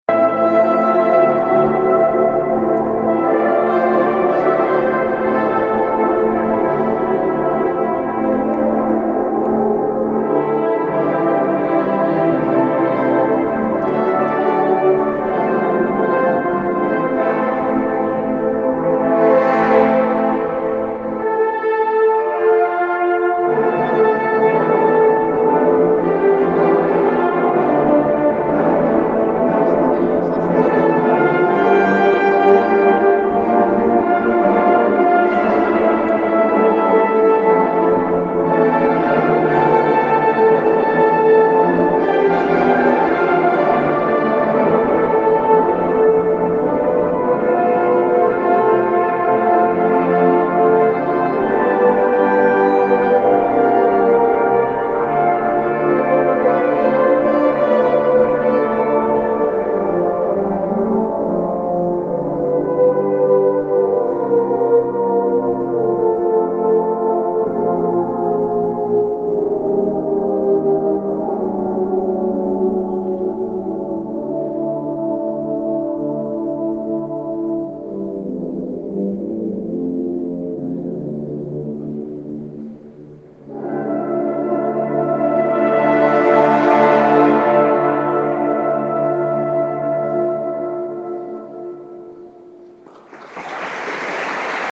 Puis, à l’angle d’une rue, nous découvrons une église où nous nous engouffrons en silence. Très rapidement ce silence fut rompu par un orchestre exclusivement formés de cuivre et maniés par de jeunes gens.